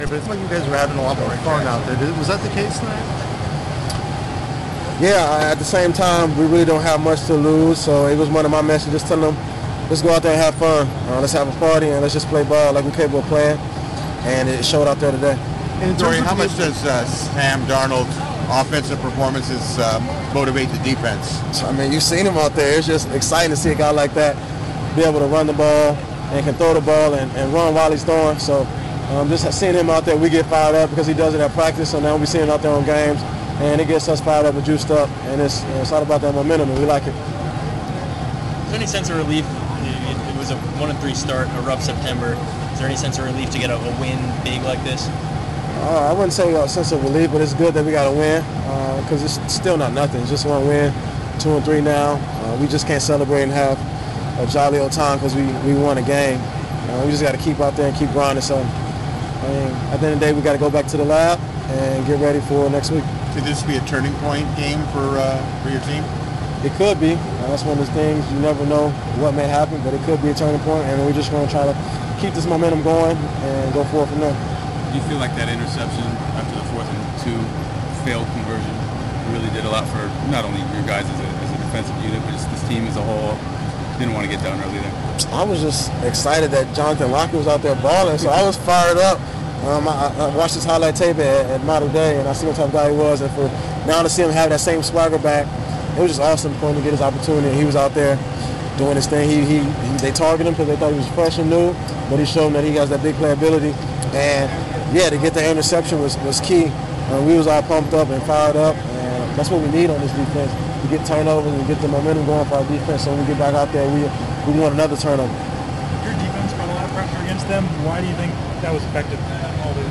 Adoree Jackson talks about the win over ASU